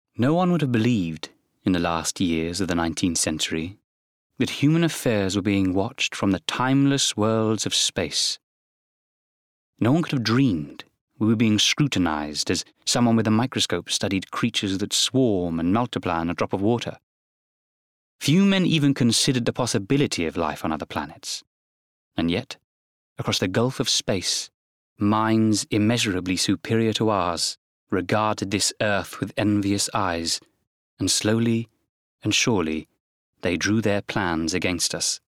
20/30's RP/London, Confident/Natural/Direct
• Audio Books